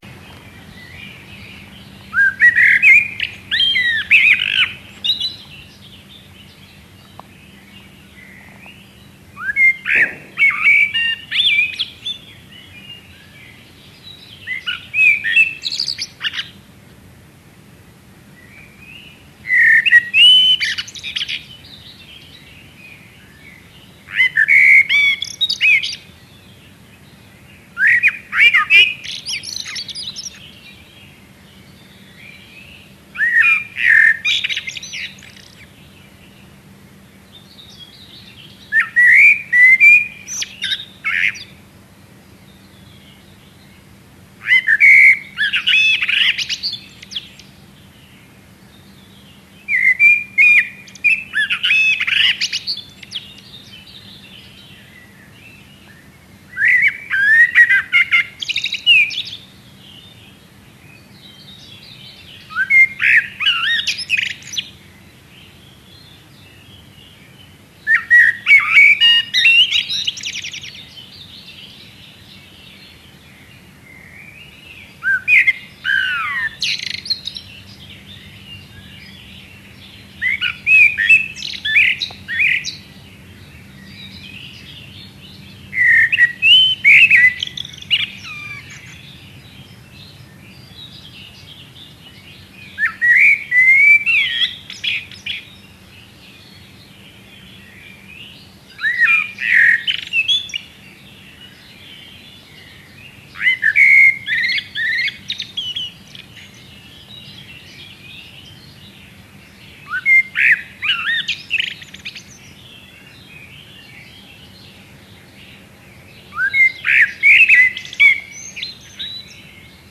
Su canto no es algo monótono y parece una pieza musical. Además siempre los escucho de manera individual, es decir que cantan estando solos, o por lo menos de uno en uno.
Su canto es rico en sonidos diferentes, la modulación sería muy armónica.
mirlo_comun.mp3